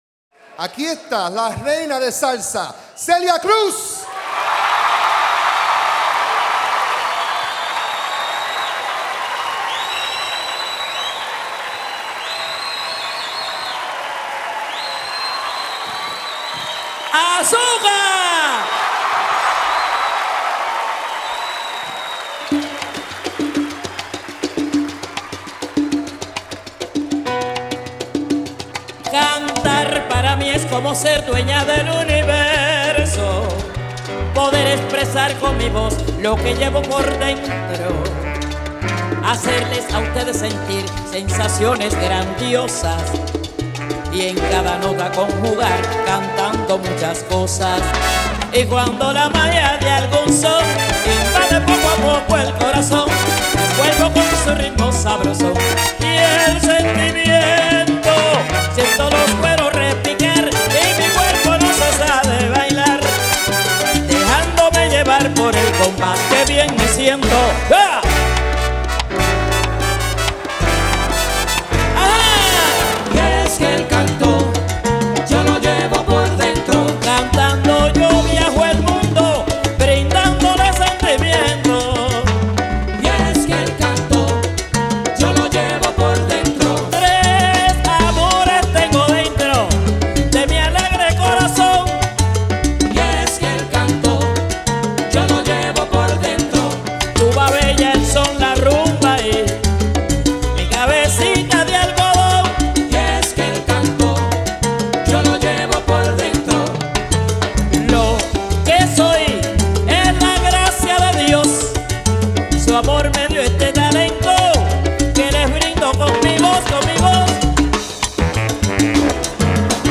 Do they have salsa here?